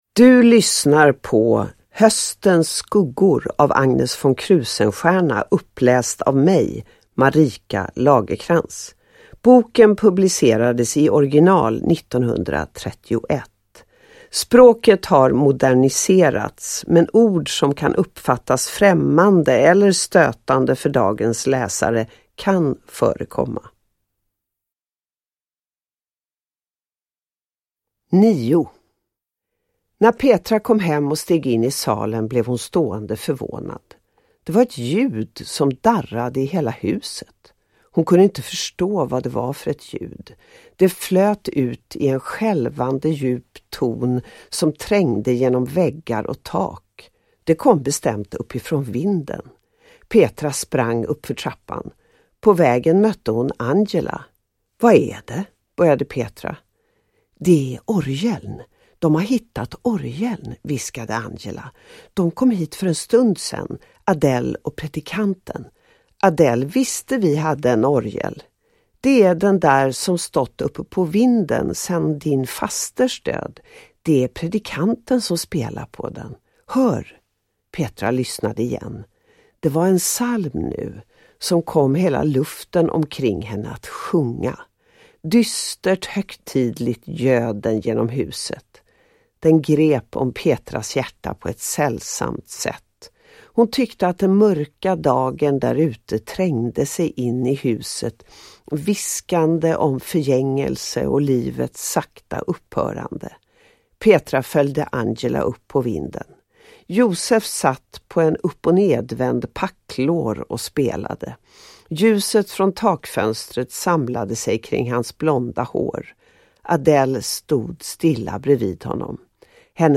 Höstens skuggor / Ljudbok